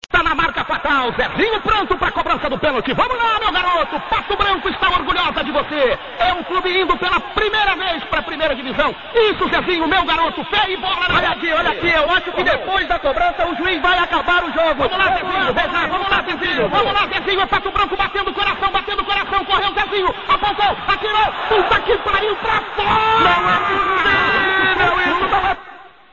narracaodojogo_patobranco.mp3